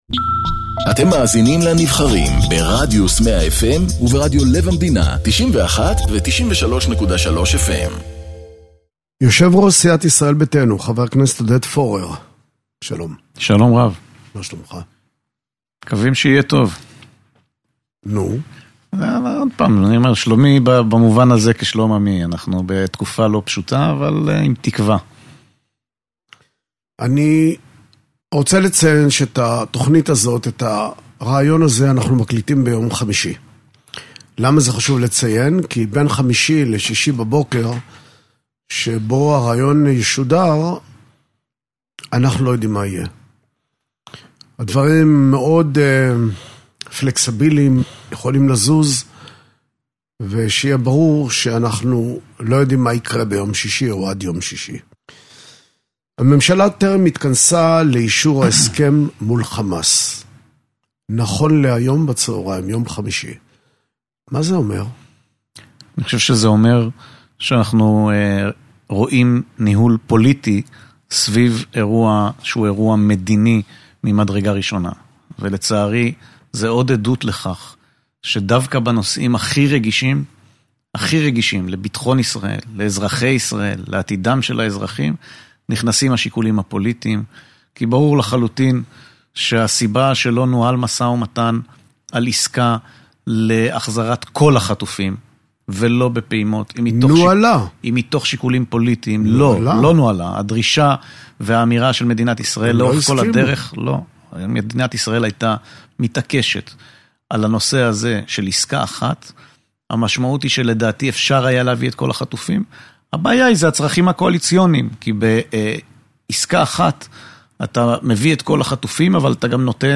מראיין את יושב ראש סיעת "ישראל ביתנו", חבר הכנסת עודד פורר